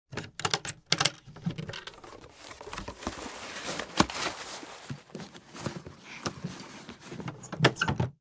chest_1.ogg